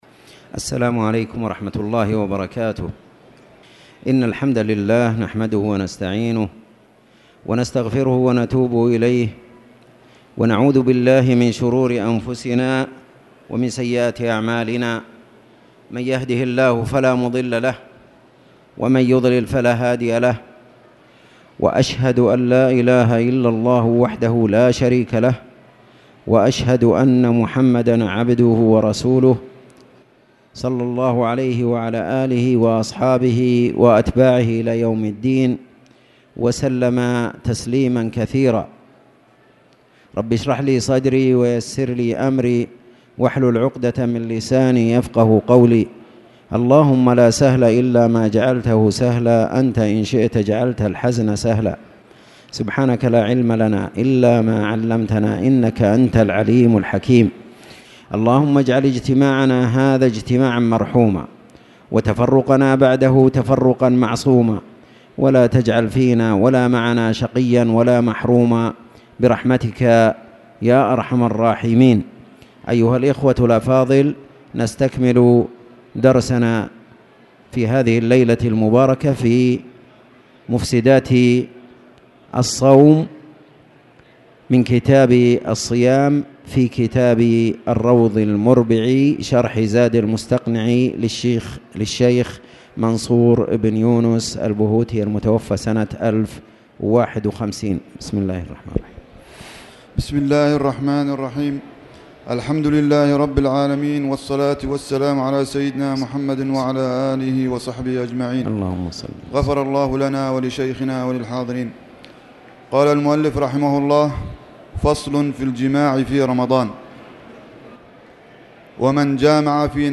تاريخ النشر ٥ ربيع الثاني ١٤٣٨ هـ المكان: المسجد الحرام الشيخ